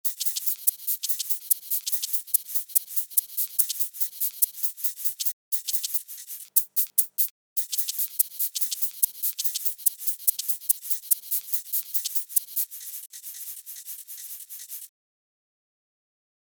Marakasy
Obecnie są to drewniane kule wypełnione piaskiem lub śrutem.
Instrument o nieokreślonej wysokości dźwięku
Marakasy trzyma się za rączkę i potrząsa nimi jak grzechotką.
Dźwięki instrumentów są brzmieniem orientacyjnym, wygenerowanym w programach:
Kontakt Native Instruments (głównie Factory Library oraz inne biblioteki) oraz Garritan (Aria Player).
Marakasy.mp3